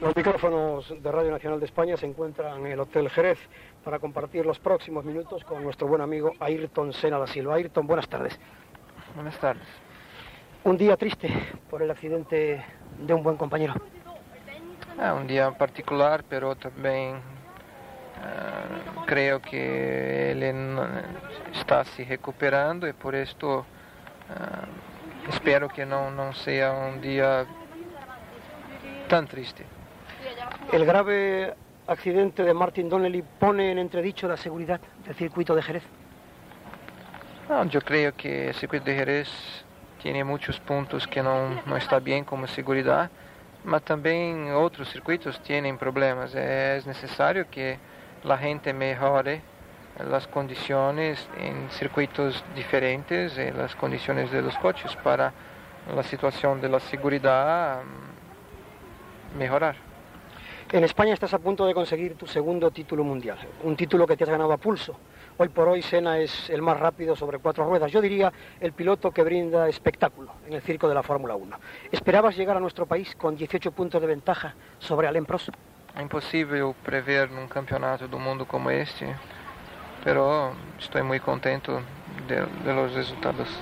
Entrevista al pilot de Fórmula 1, Ayrton Senna, feta el divendres del Gran Premi d'Espanya de Fórmula 1 al circuit de Jerez (Espanya).
Esportiu